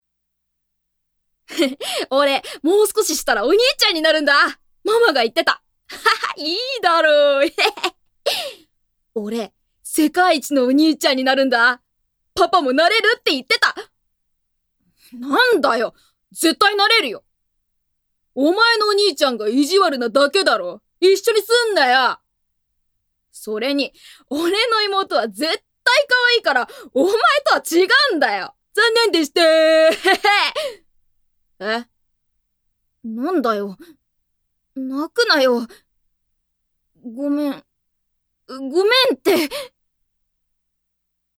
◆男の子◆